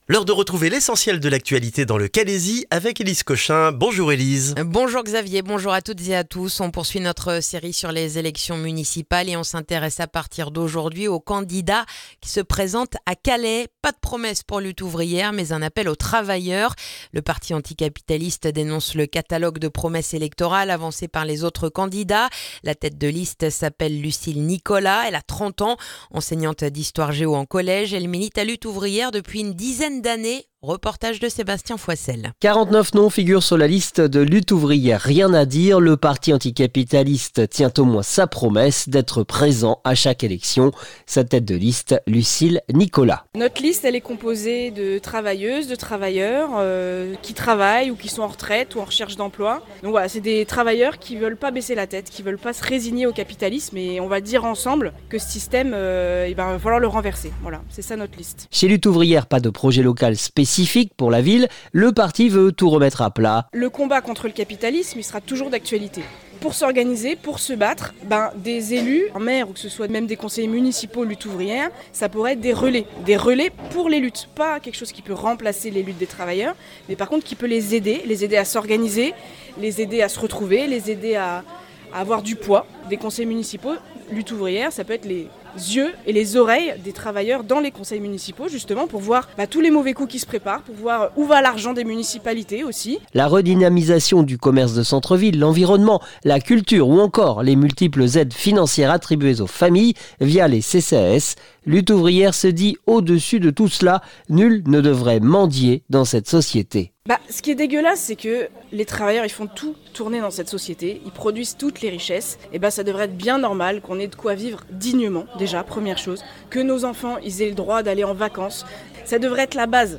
Le journal du vendredi 6 mars dans le calaisis